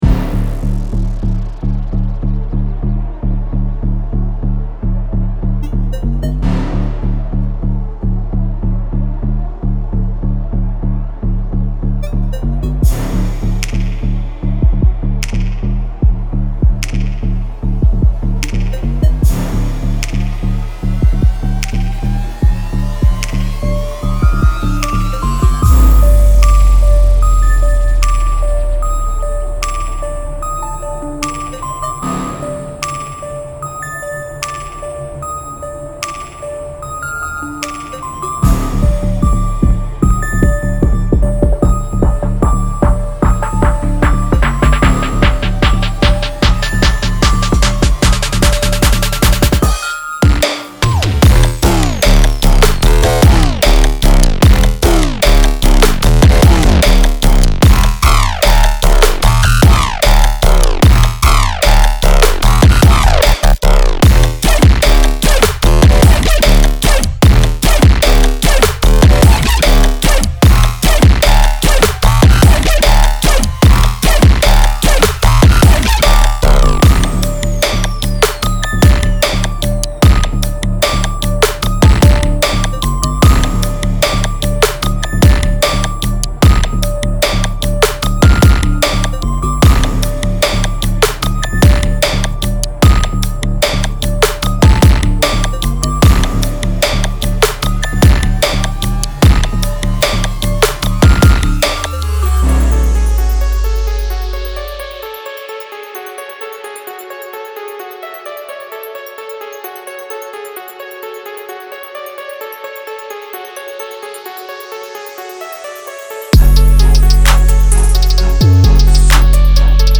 DubstepTrap
由开裂的圈套器和有力的打击声组成，并具有精确的瞬变信号，已准备好，可以有效地减少混音。
• 120 Snappy drum hits
• 20 Thundering drum loops (Full bounces and stem bounces)
• 20 Punchy bass Loops (including Midi files)
• 20 Haunting chord Loops (including Midi files)
• 20 Aggressive melody Loops (including Midi files)
• Tempos – 130, 150 BPM